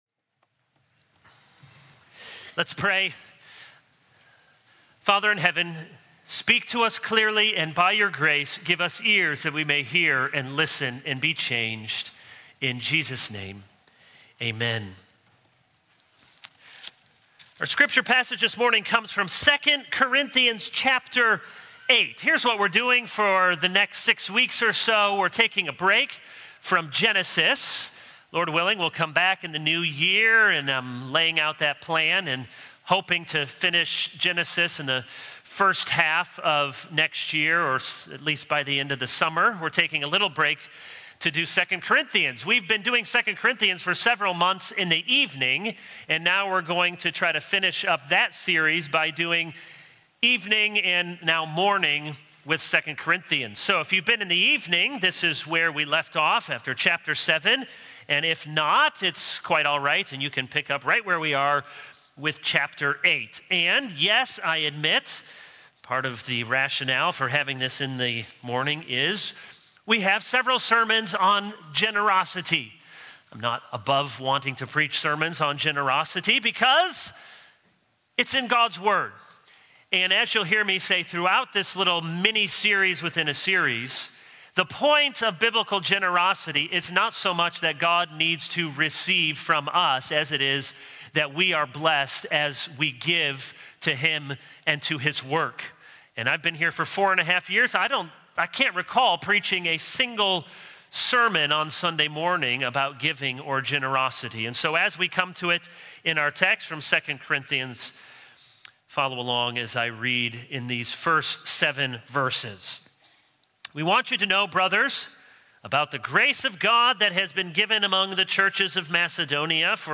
This is a sermon on 2 Corinthians 8:1-7.